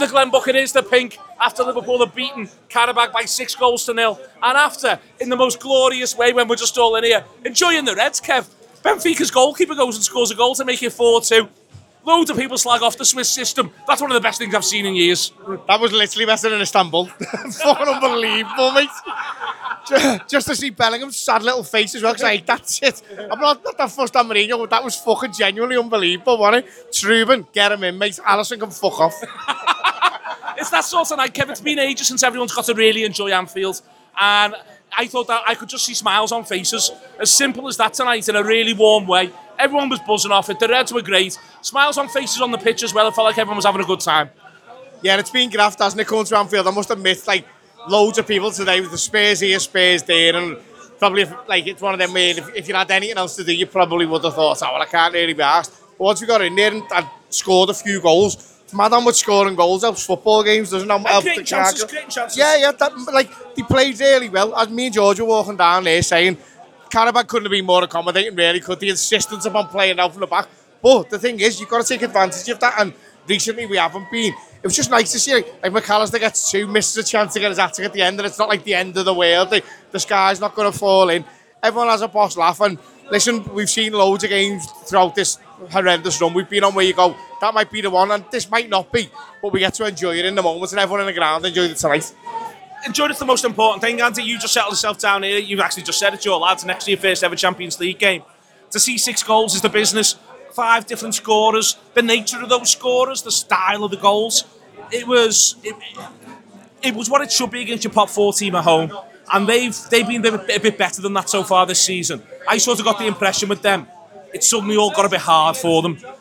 by | Jan 28, 2026 | app, Podcast, Post-Match Show, TAW Player | 0 comments